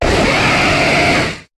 Cri de Rexillius dans Pokémon HOME.